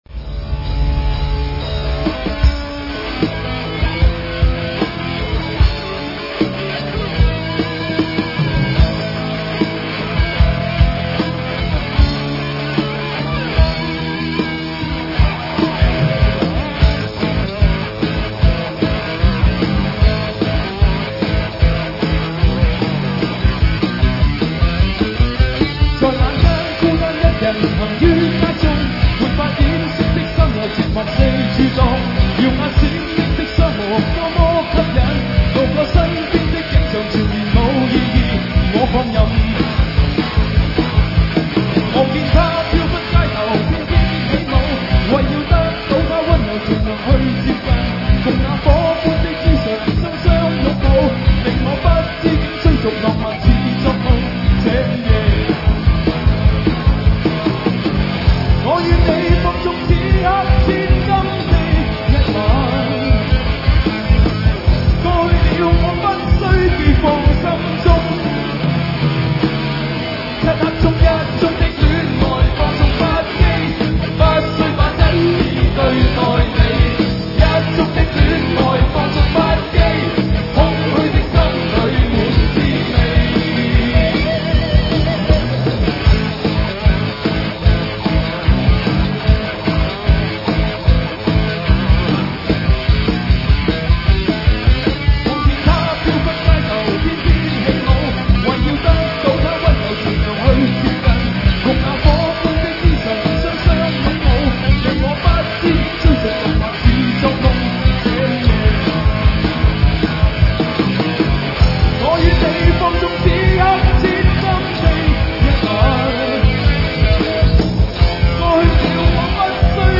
主唱